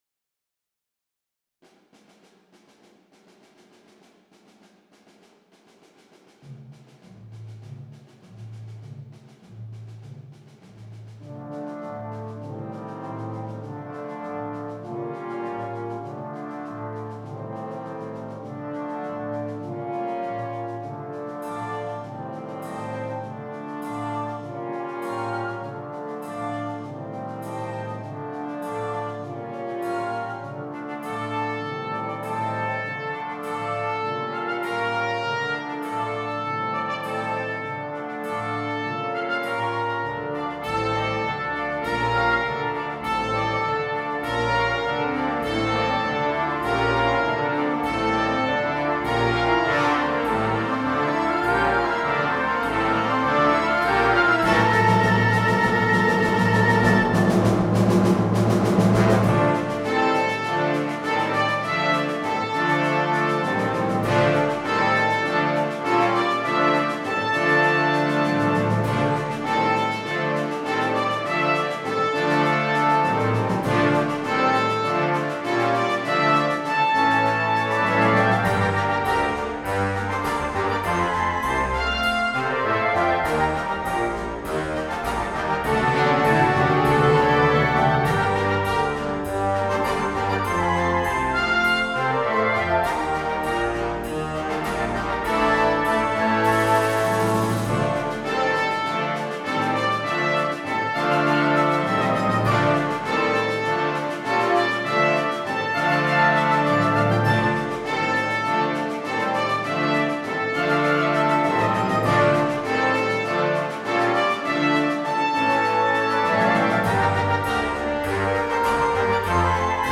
for Wind Band
A highly descriptive patrol, full of menacing Roman Legions!